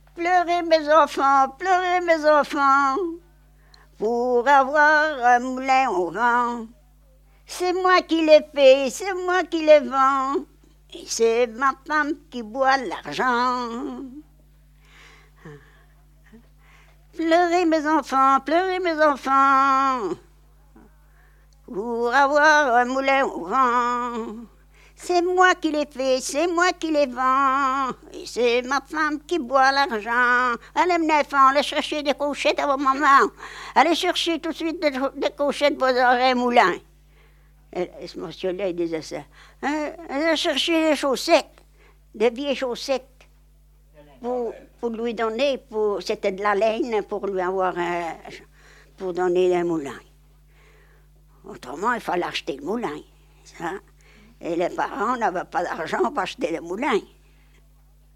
Genre : chant
Type : chanson de travail
Support : bande magnétique
Explication à la fin de la séquence.